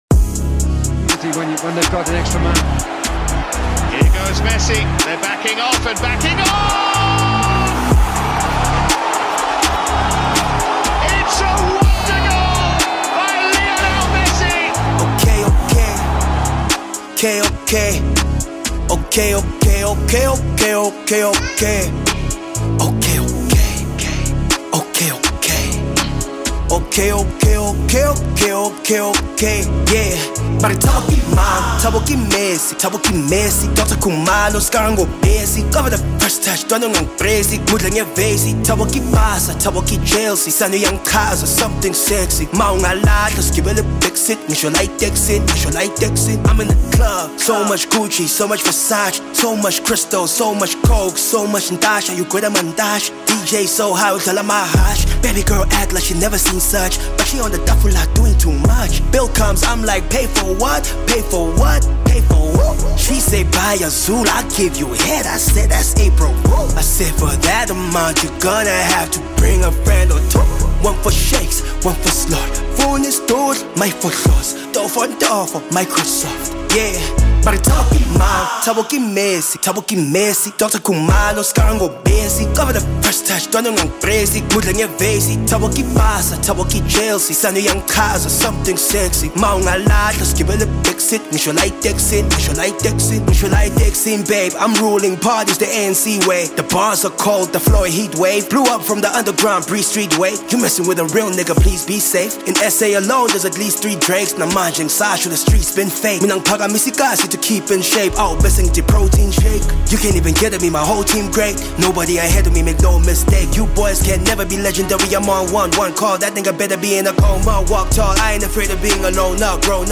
is an up-and-coming rapper